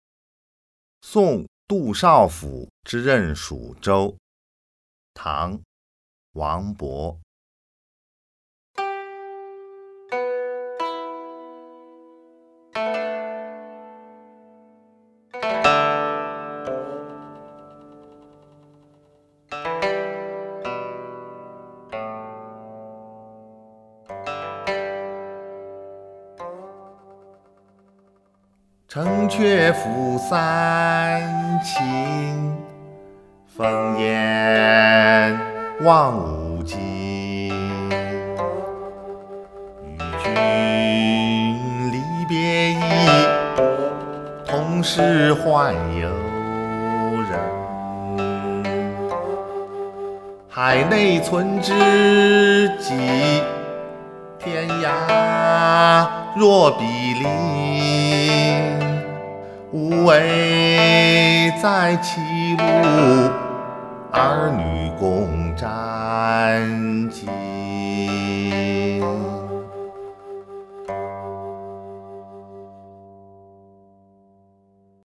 ［唐］王勃 送杜少府之任蜀州（吟咏）